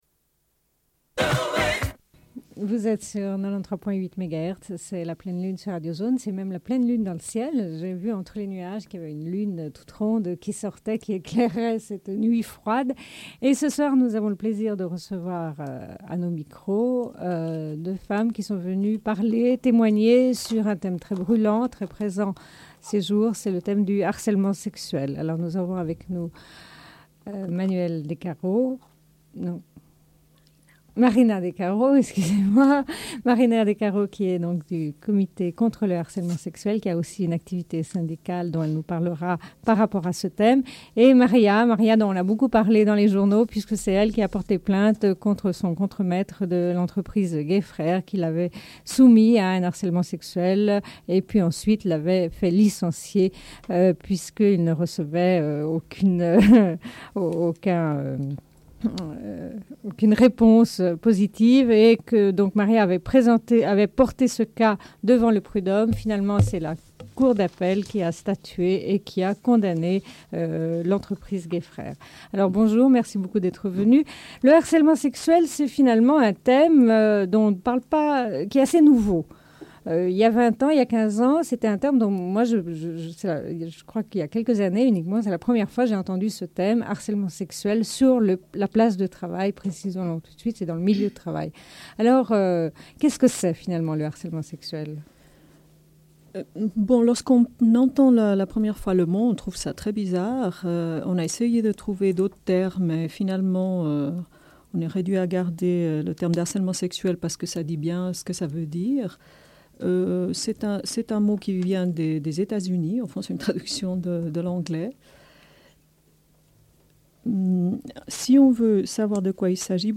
Une cassette audio, face A31:27
Radio Enregistrement sonore